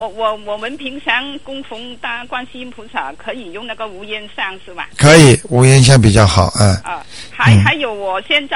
目录：☞ 2008年05月_剪辑电台节目录音集锦